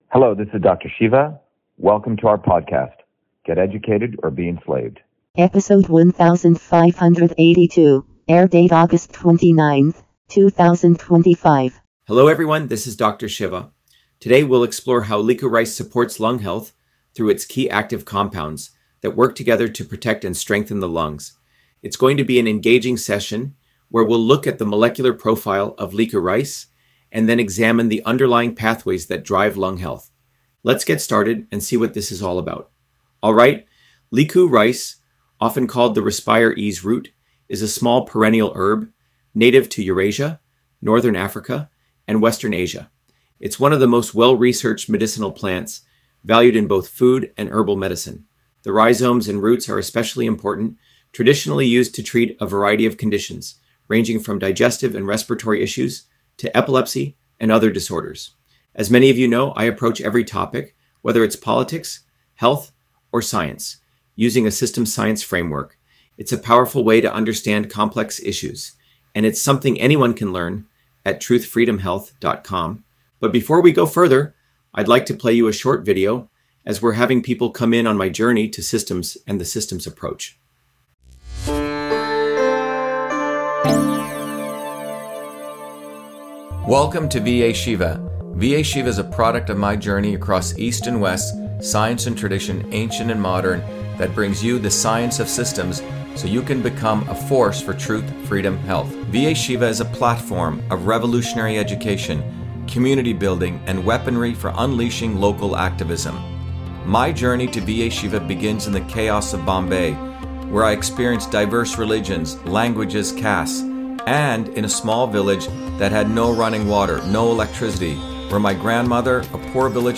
In this interview, Dr.SHIVA Ayyadurai, MIT PhD, Inventor of Email, Scientist, Engineer and Candidate for President, Talks about Licorice on Lung Health: A Whole Systems Approach